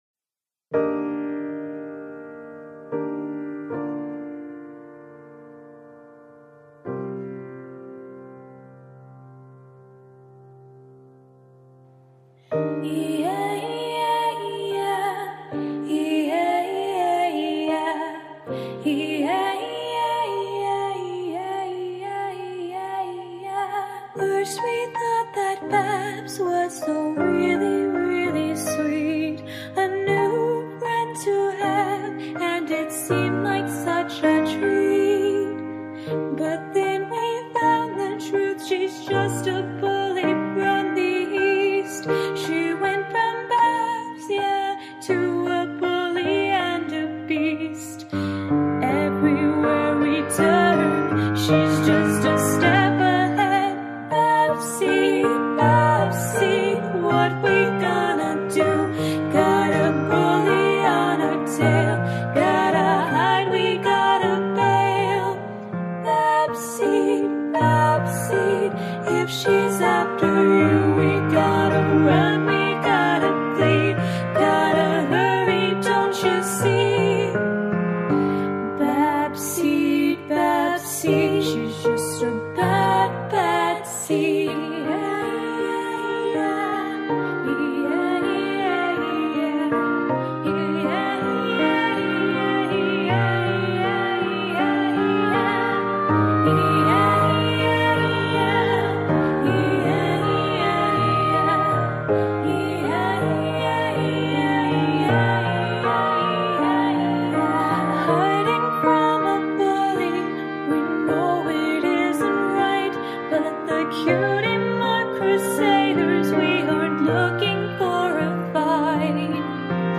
Vocals belong to me =3